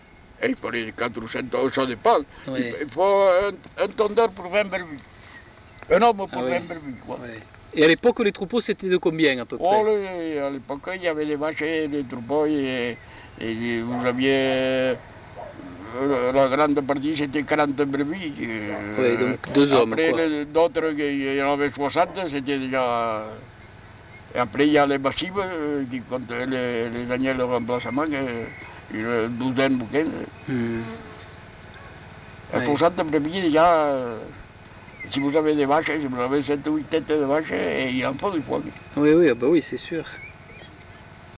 Aire culturelle : Couserans
Lieu : Estouéou (lieu-dit)
Genre : témoignage thématique